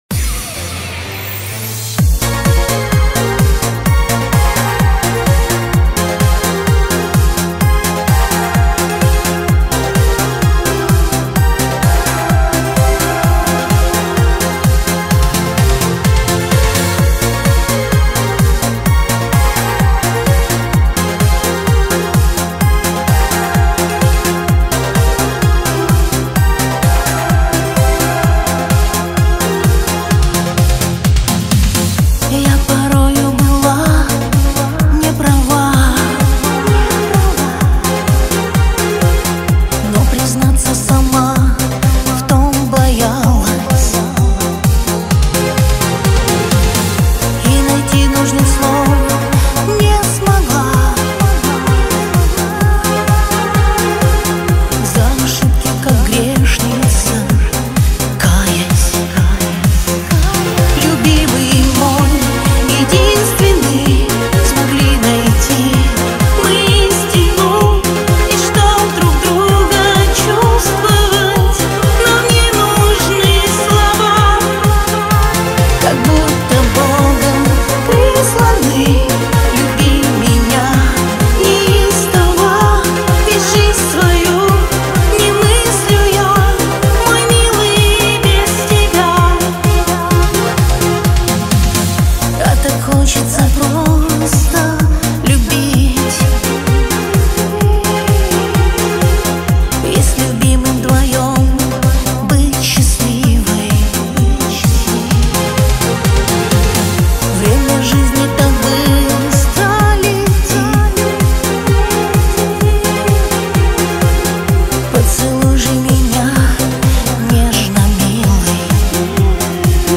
Русский шансон 2025